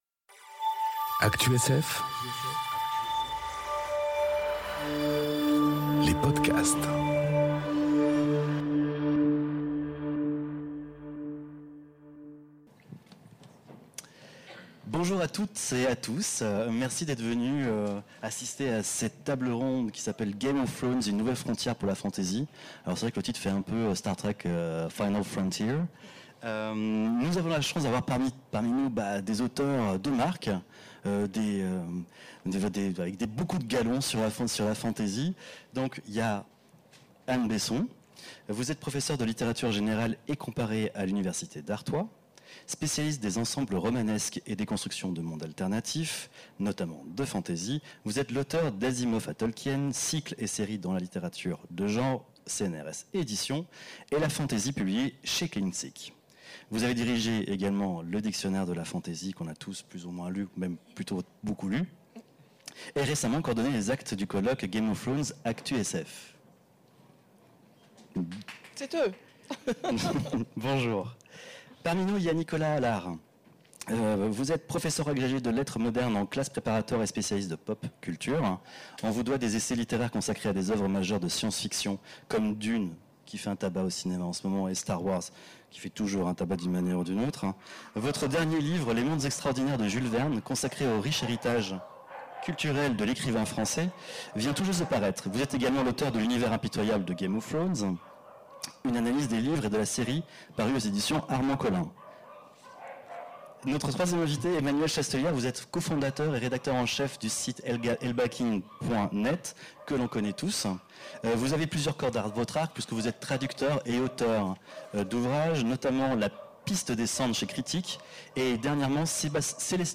Imaginales 2021 : Game of Thrones, une nouvelle frontière pour la fantasy ?